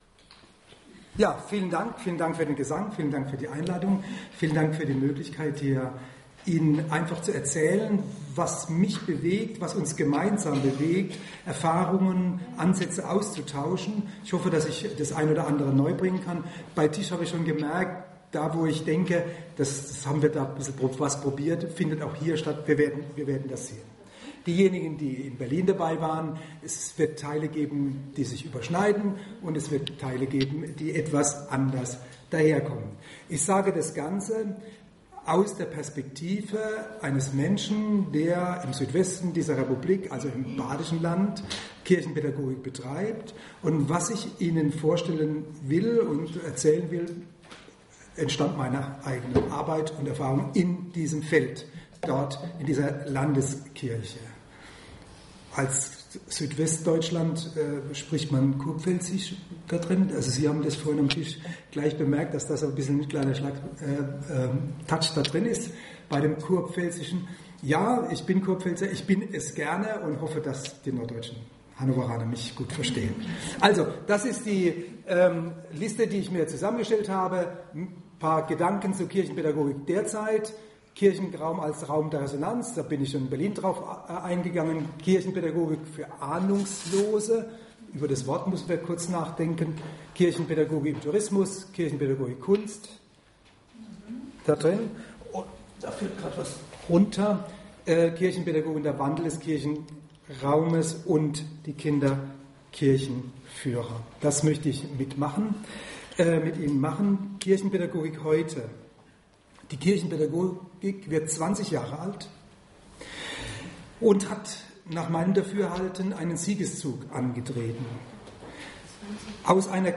Neue Ansätze in der Kirchenpädagogik, Vortrag
September 2019 im RPI Loccum im Rahmen des Treffpunkt Kirchenpädagogik (im Originalton 86 Minuten) Anhören 2.